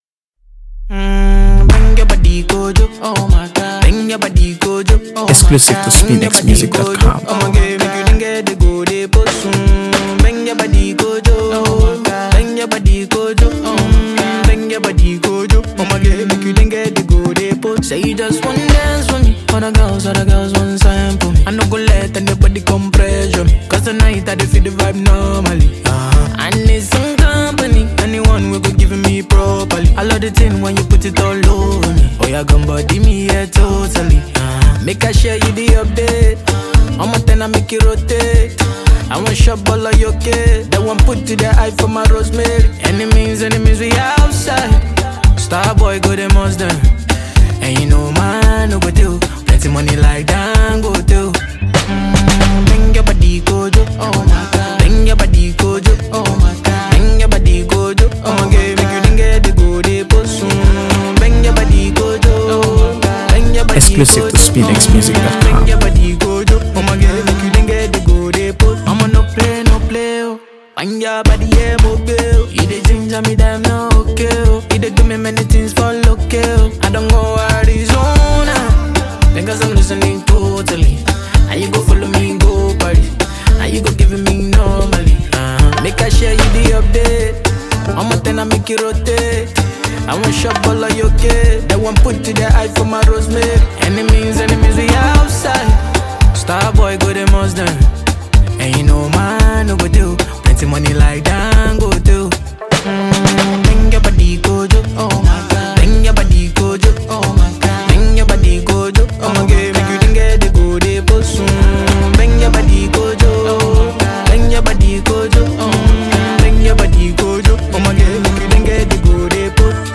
AfroBeats | AfroBeats songs
smooth vocals with hypnotic beats